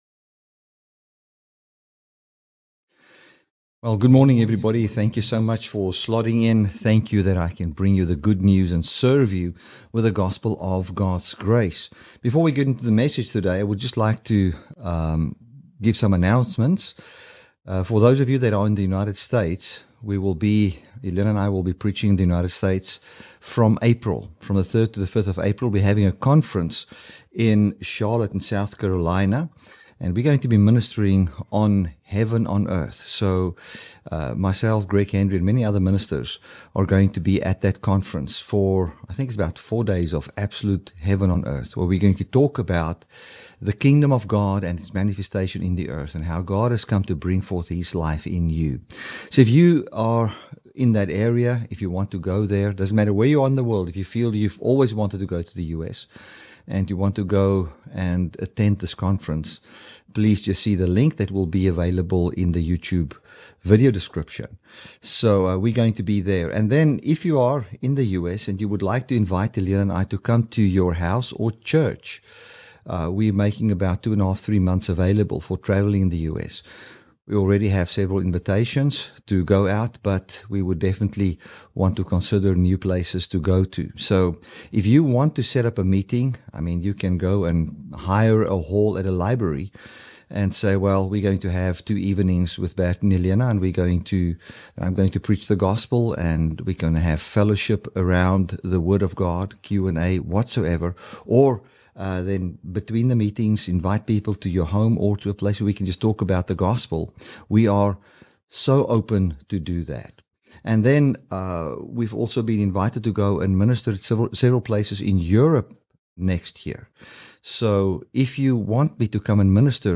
Audio Messages for Dynamic Love Ministries.